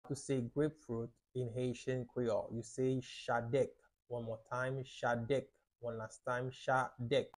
How to say “Grapefruit” in Haitian Creole – “Chadèk” pronunciation by a native Haitian Teacher
“Chadèk” Pronunciation in Haitian Creole by a native Haitian can be heard in the audio here or in the video below:
How-to-say-Grapefruit-in-Haitian-Creole-–-Chadek-pronunciation-by-a-native-Haitian-Teacher.mp3